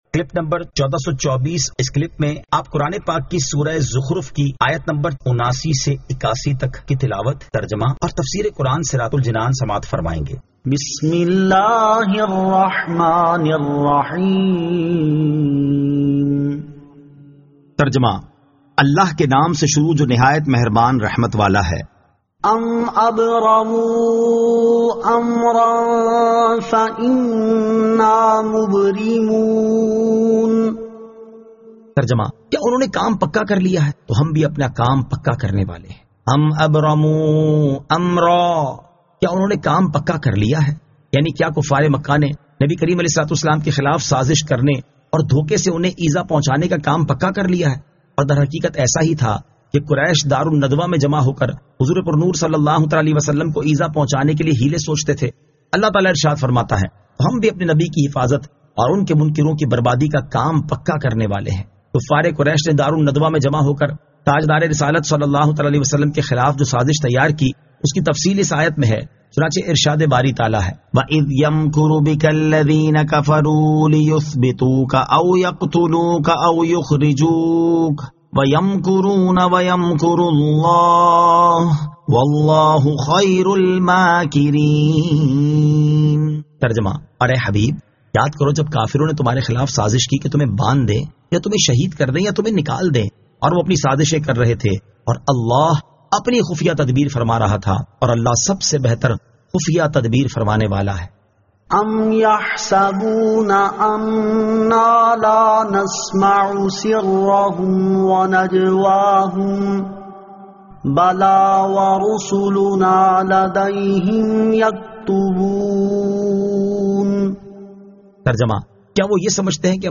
Surah Az-Zukhruf 79 To 81 Tilawat , Tarjama , Tafseer